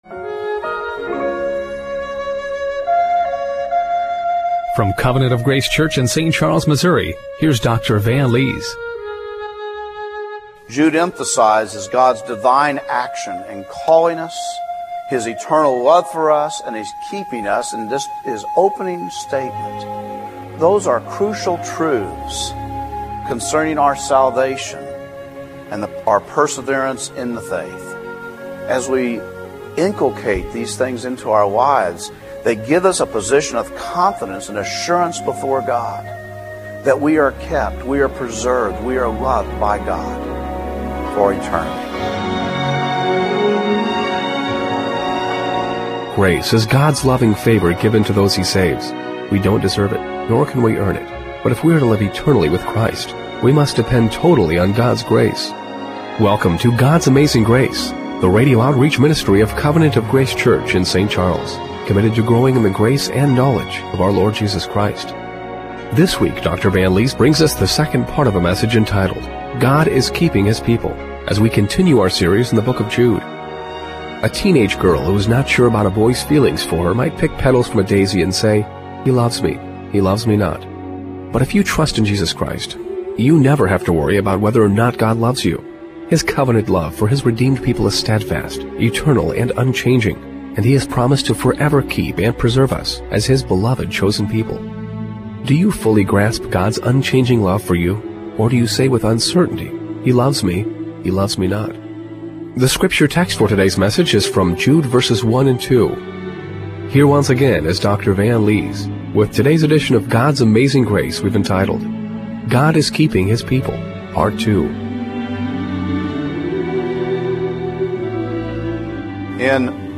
Jude 1:1-2 Service Type: Radio Broadcast Do you fully grasp God's unchanging love for you